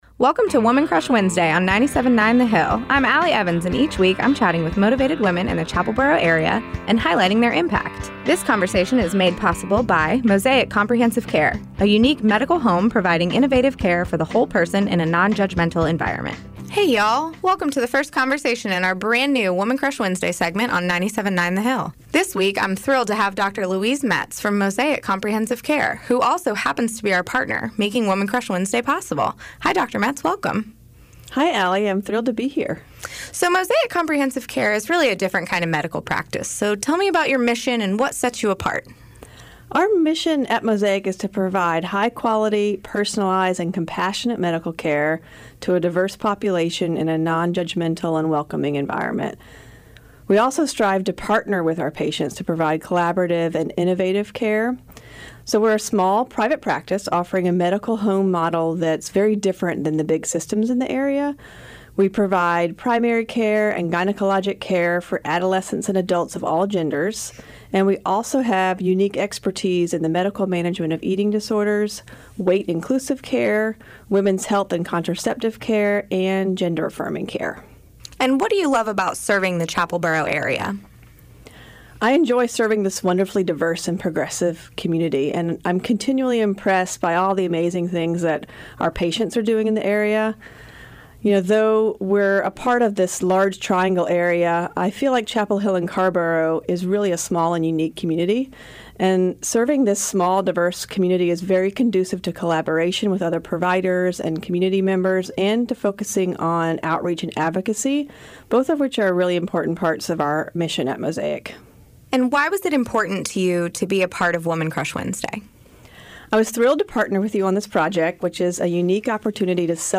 ” a three-minute weekly recurring segment made possible by Mosaic Comprehensive Care that highlights motivated women and their impact both in our community and beyond.